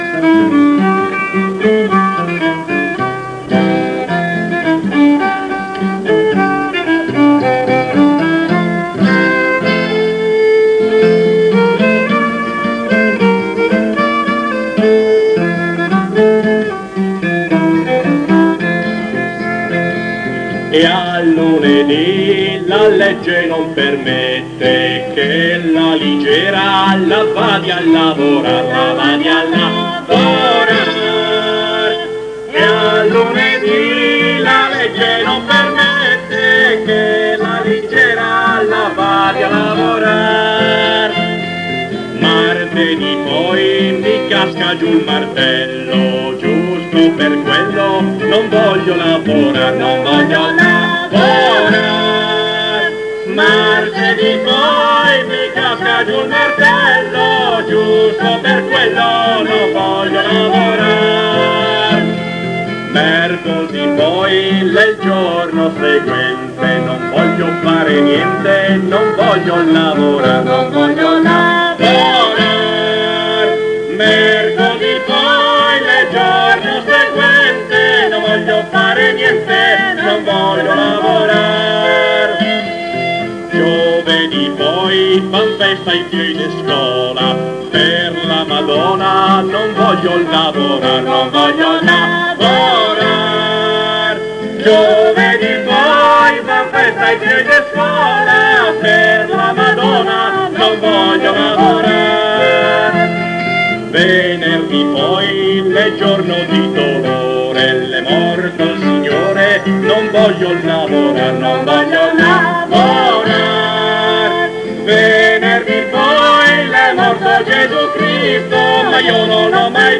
ALMANACCO POPOLARE DAL VIVO